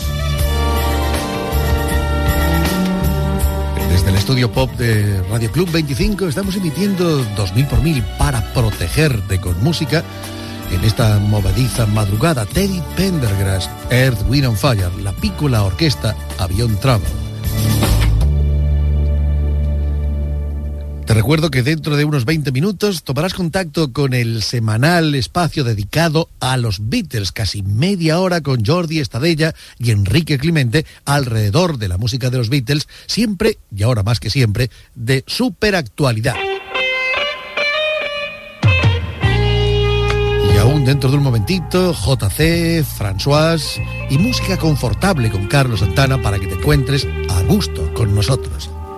Final d'un tema musical, avanç de les properes seccions i col·laboradors.
Musical
FM